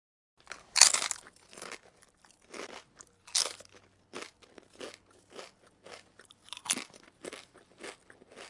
人类吃薯片的声音
描述：人类听起来吃薯条
声道立体声